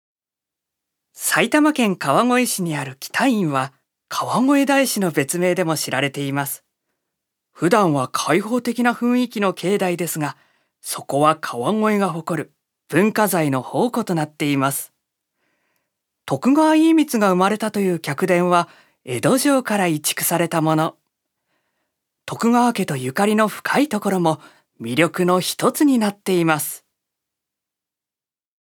所属：男性タレント
音声サンプル
ナレーション１